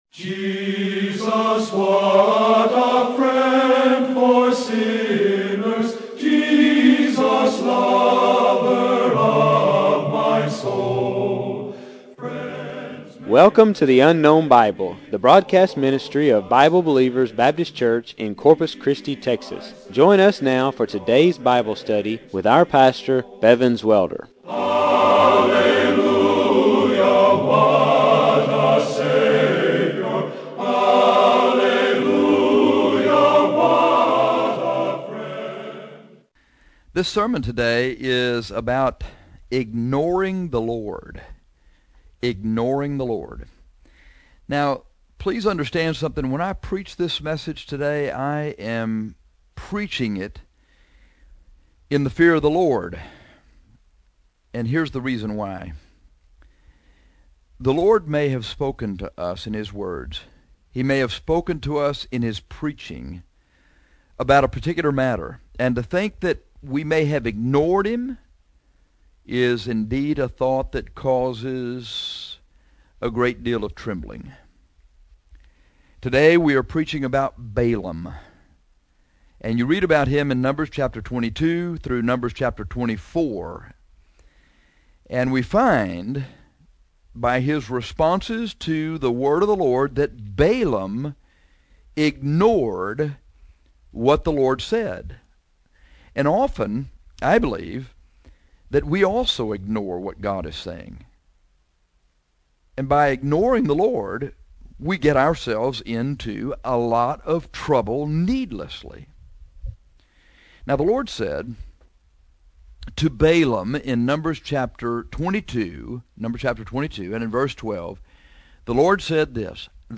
This sermon is about ignoring the Lord.